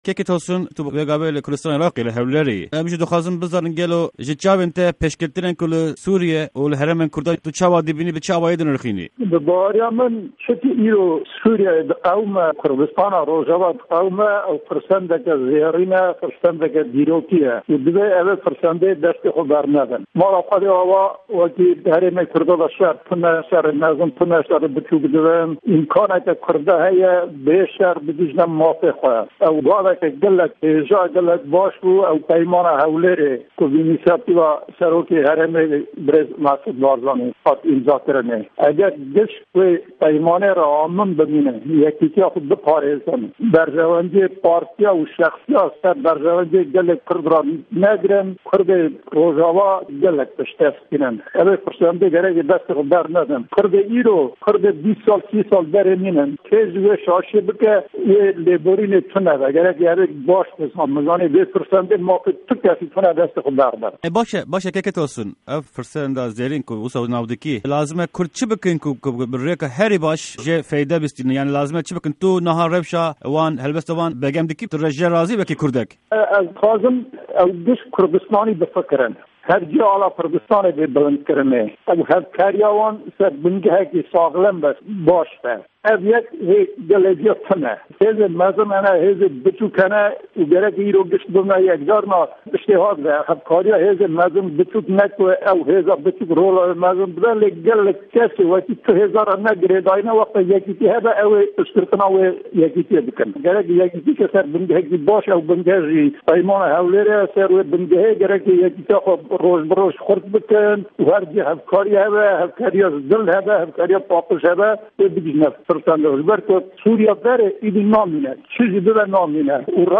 Hevpeyvîn_TR